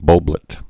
(bŭlblĭt)